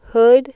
Figura 5 – Ilustração de Formantes da Base de Hillenbrand [bibcite key=hillenbrand1995acoustic] para o sinal ‘w01oo.wav’ – Voz Femina, pronunciando hood: